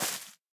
Minecraft Version Minecraft Version 1.21.5 Latest Release | Latest Snapshot 1.21.5 / assets / minecraft / sounds / block / cherry_leaves / break2.ogg Compare With Compare With Latest Release | Latest Snapshot